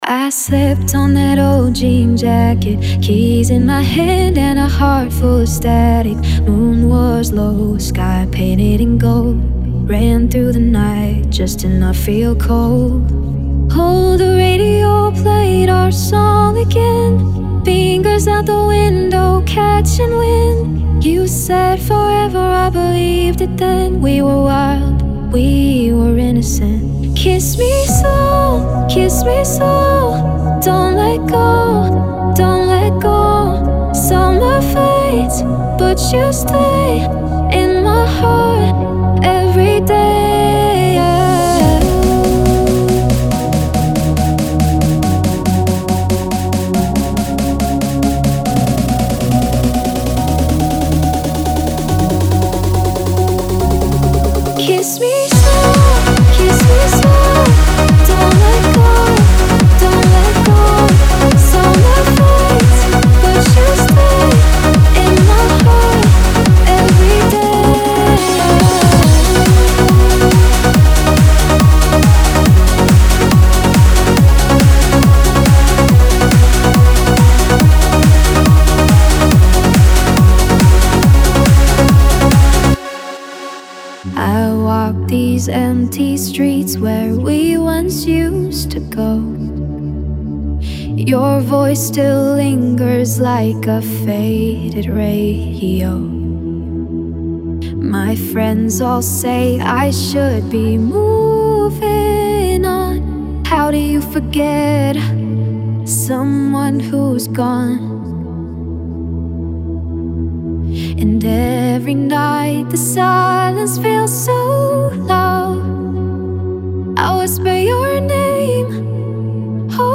Trance Vocals
5 x Full Top-Quality Vocal Trance Construction Kits.
Kits 139 - 142 BPM & Key-Labelled.
Vocals Wet & Dry Versions.